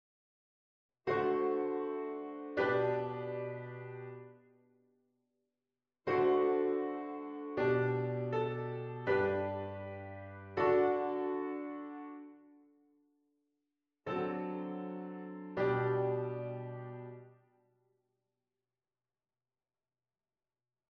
Dit komt omdat het juist de oplossing van hets eptime is die wordt verdubbeld: zie voorbeeld 19a.
V7 -  I6